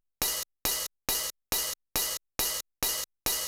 OP HH     -L.wav